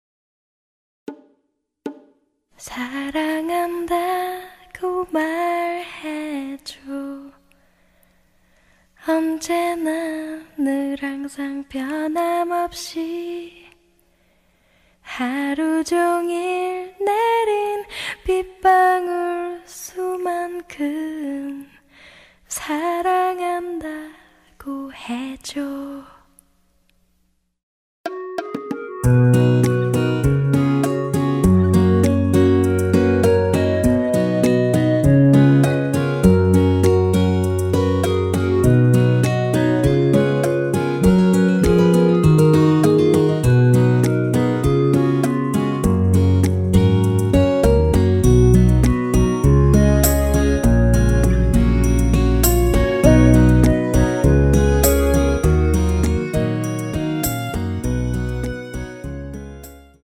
원키 멜로디 포함된 시작부분 여성 보컬 추가된 MR입니다.(미리듣기 참조)
F#
앞부분30초, 뒷부분30초씩 편집해서 올려 드리고 있습니다.
중간에 음이 끈어지고 다시 나오는 이유는